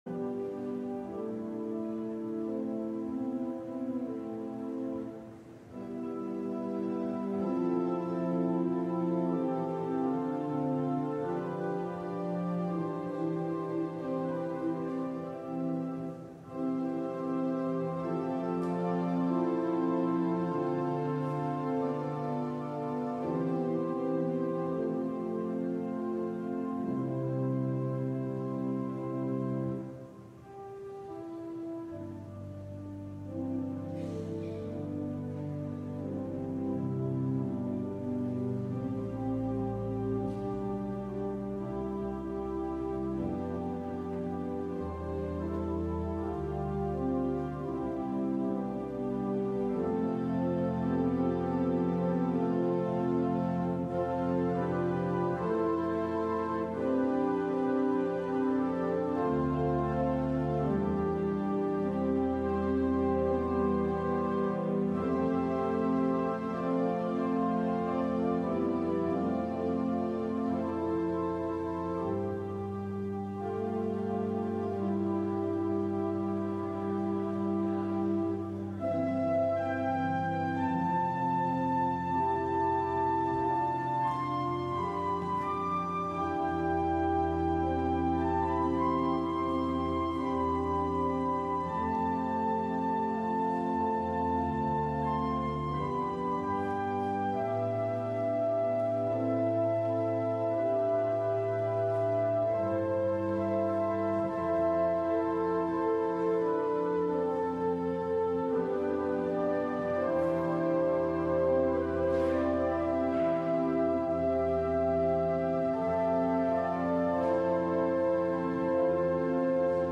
LIVE Evening Worship Service - The Surprising Centurion